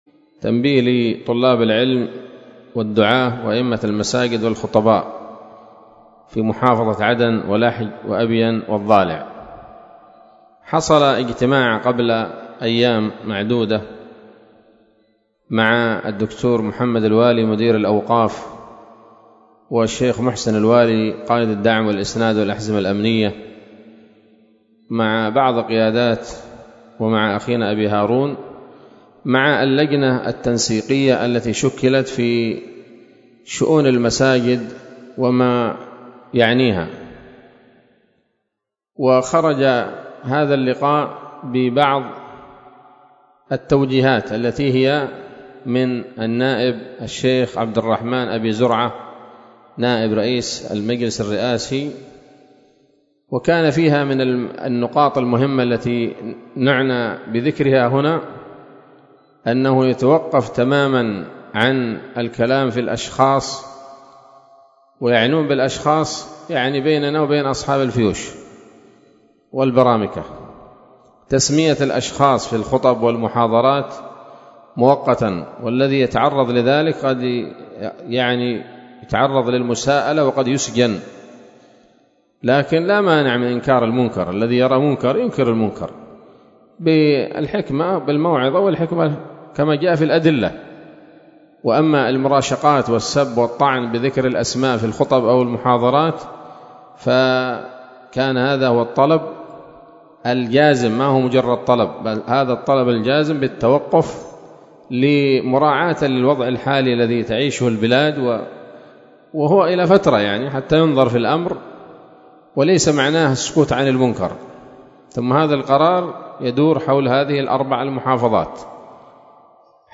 كلمة قيمة
بدار الحديث السلفية بصلاح الدين